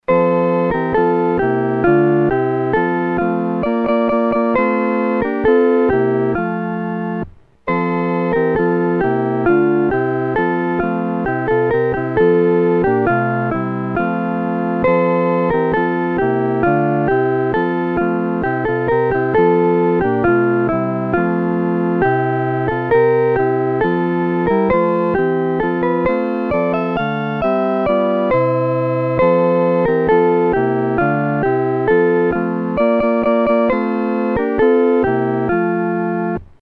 合奏（四声部）